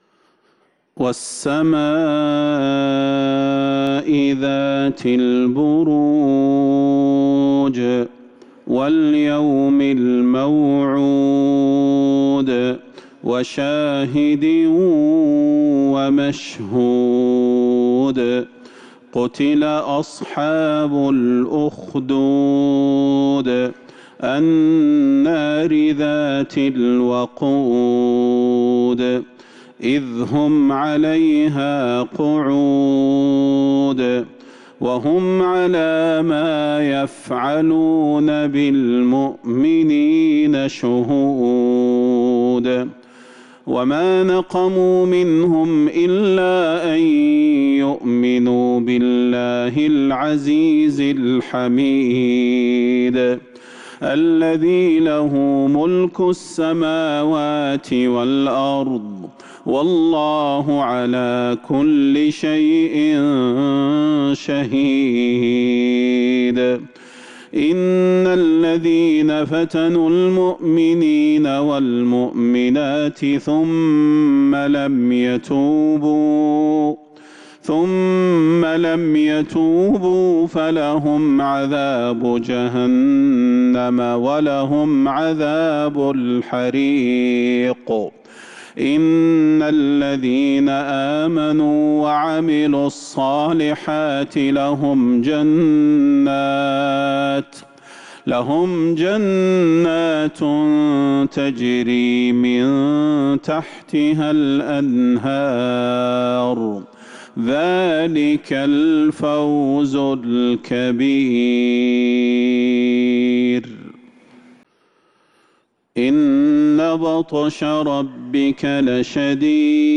سورة البروج | شعبان 1447هـ > السور المكتملة للشيخ صلاح البدير من الحرم النبوي 🕌 > السور المكتملة 🕌 > المزيد - تلاوات الحرمين